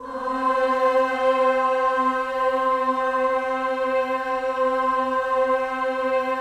VOWEL MV09-R.wav